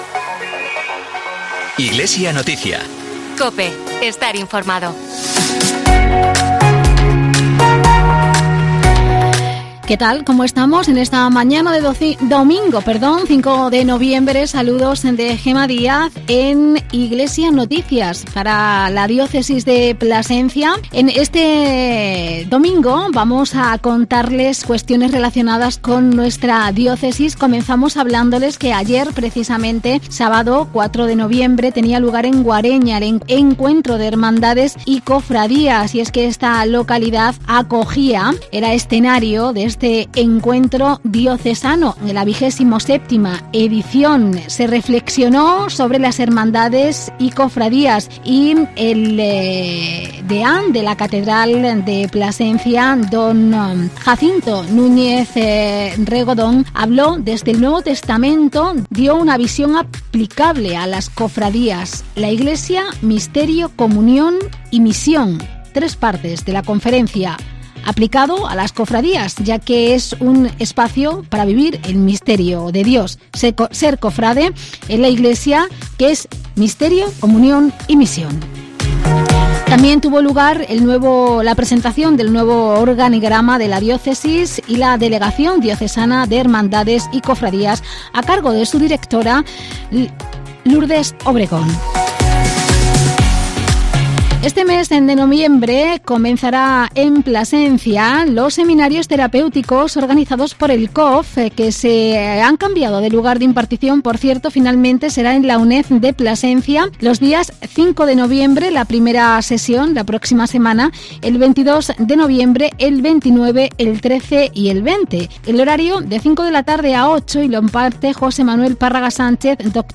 Informativo diocesano semanal de plasencia